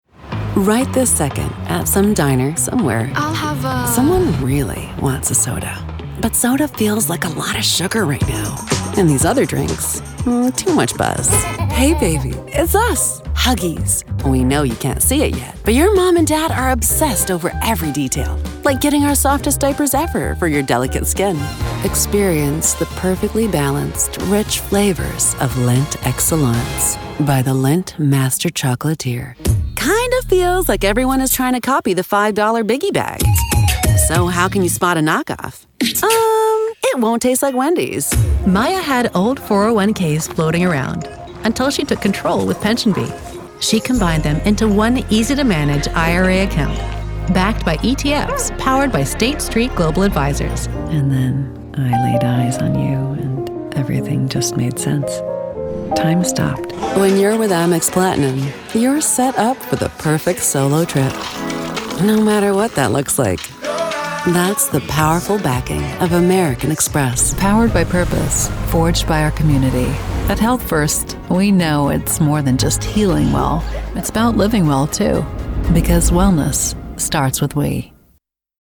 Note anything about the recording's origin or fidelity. Equipment Sennheiser MKH416 microphone, Double-Walled soundproof booth by Kube